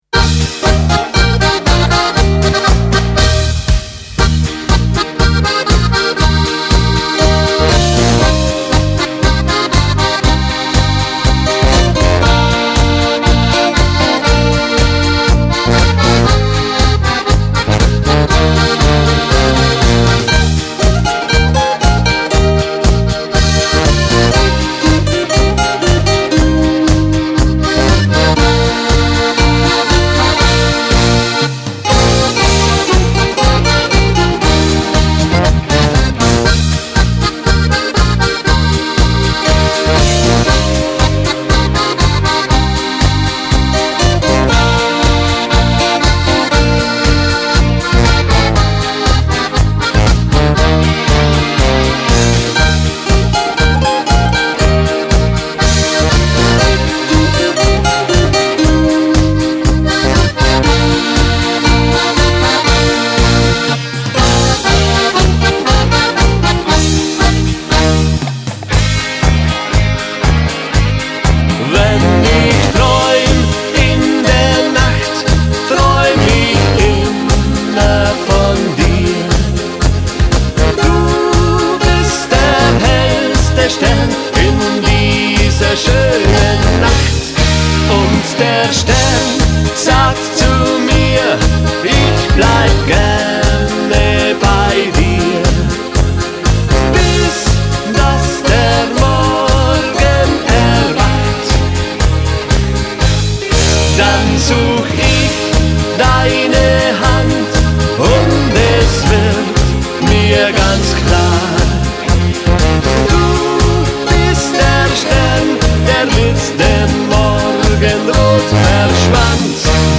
• Akkordeon
• Allround Partyband
• Duo/Trio/Quartett
• Volksmusik